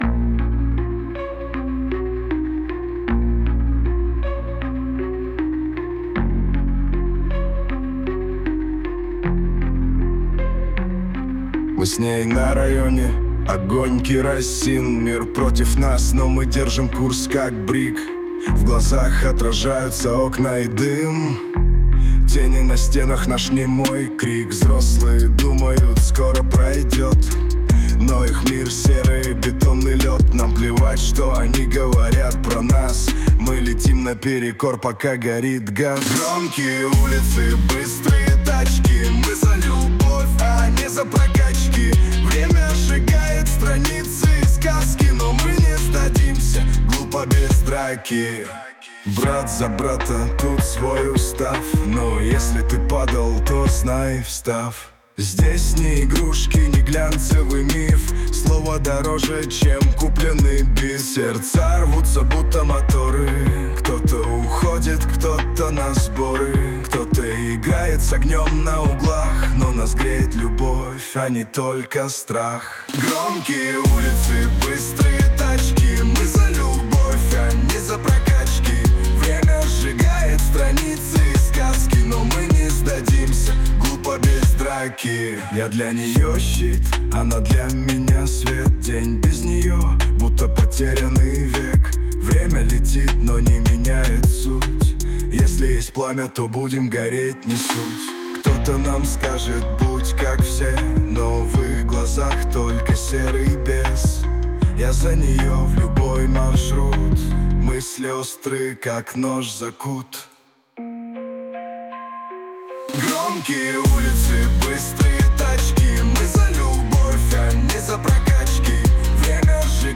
RUS, Hip-Hop, Rap | 17.03.2025 16:33